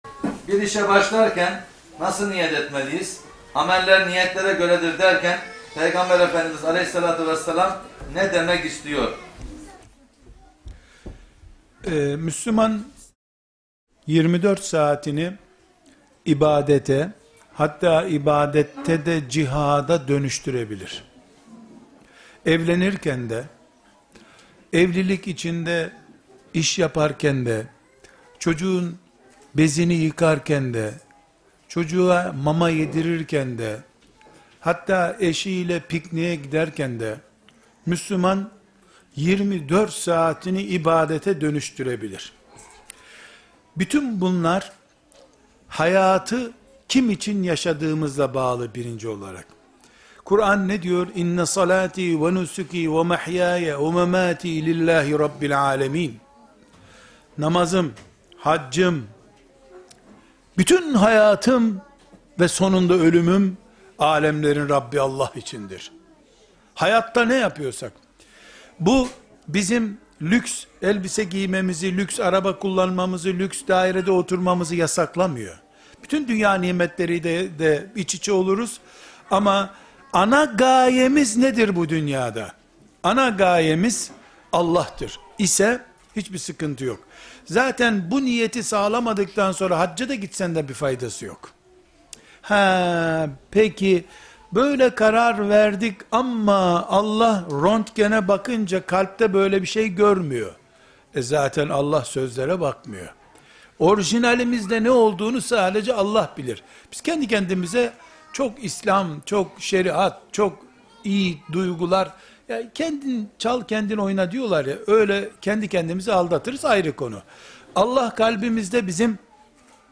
2. Soru & Cevap